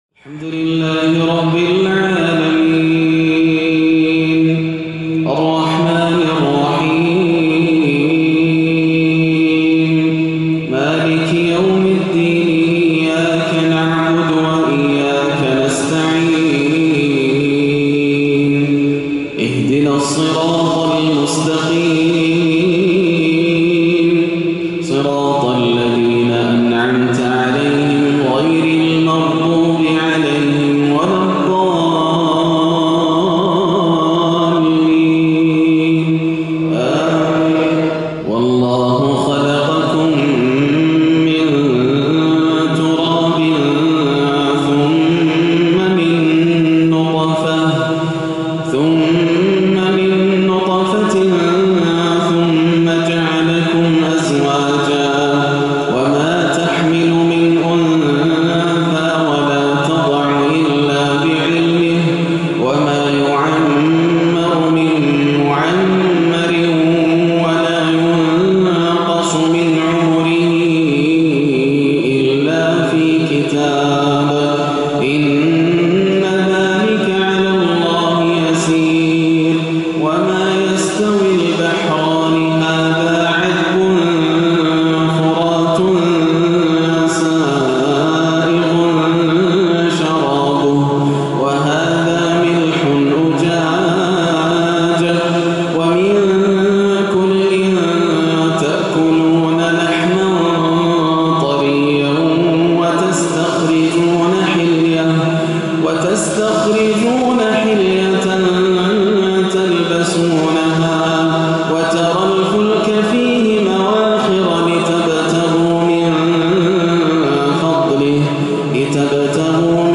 (يَا أَيُّهَا النَّاسُ أَنْتُمُ الْفُقَرَاءُ إِلَى اللهِ) عشائية كردية رائعة لأول عشائيات العام 1-1 > عام 1437 > الفروض - تلاوات ياسر الدوسري